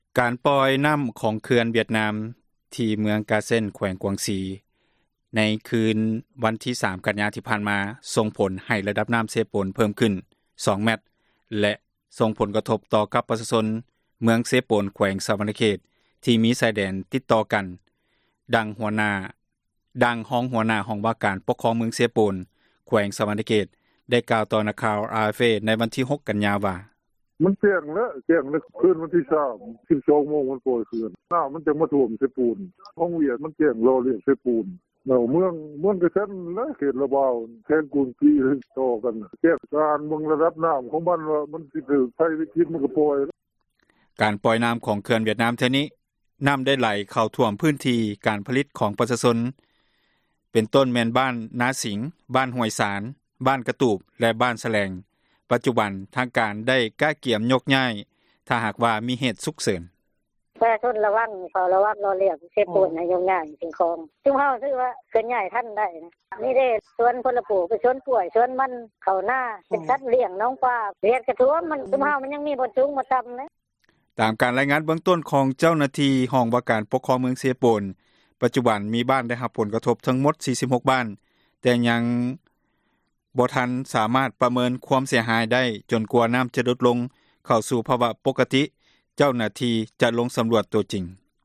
F-Vietnam-dam ວຽດນາມ ປ່ອຍນໍ້າ ອອກຈາກເຂື່ອນ ສົ່ງຜົລກະທົບ ຕໍ່ ປະຊາຊົນ ກວ່າ 20 ບ້ານ ຢູ່ເມືອງເຊໂປນ ແຂວງສວັນນະເຂດ ຣາຍລະອຽດ ເຊີນຕິດຕາມ ຮັບຟັງຂ່າວ ວິທຍຸເອເຊັຽເສຣີ.